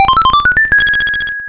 RedCoin5.wav